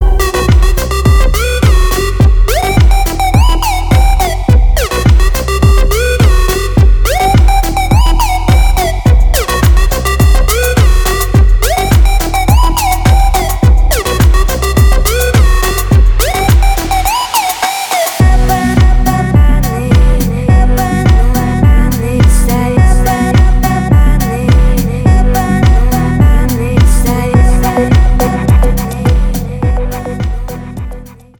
• Качество: 320, Stereo
громкие
deep house
атмосферные
Electronic